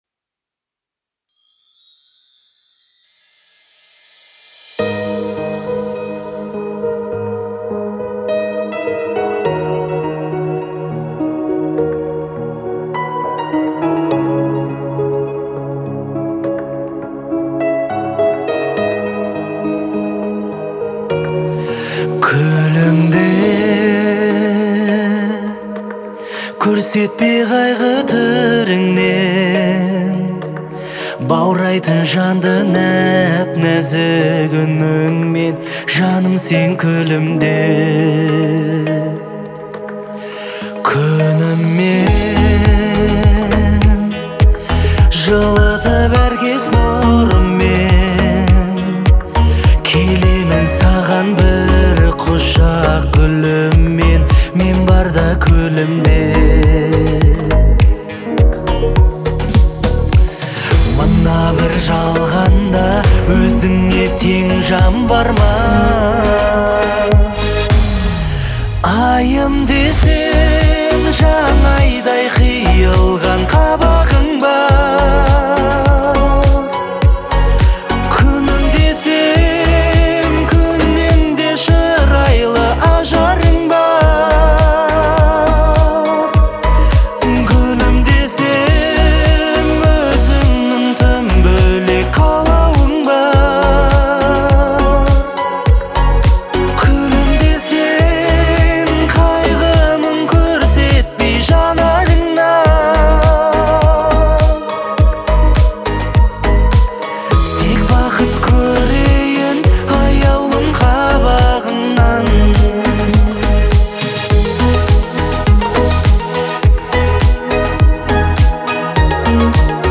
характеризуется мягкими мелодиями и душевным вокалом